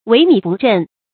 wěi mǐ bù zhèn
萎靡不振发音
成语正音 靡，不能读作“mí”。